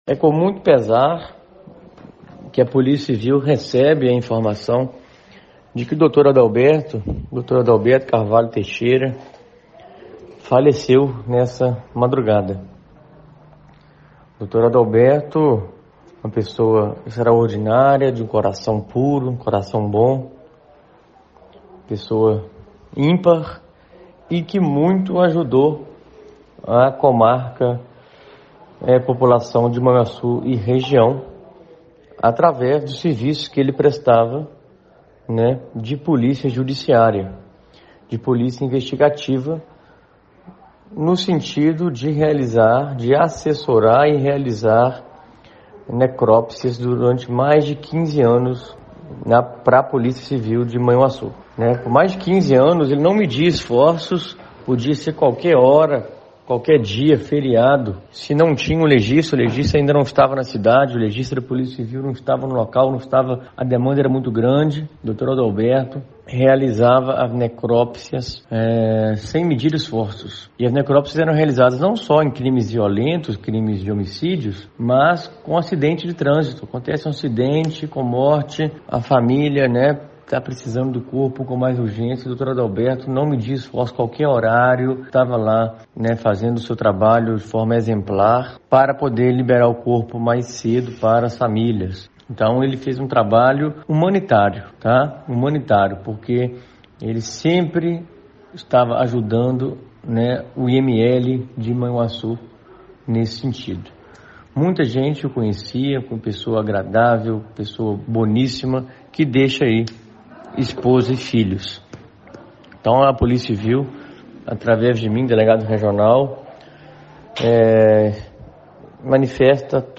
Ouça o áudio do Delegado Regional de Polícia Civil, Dr. Felipe Ornelas Caldas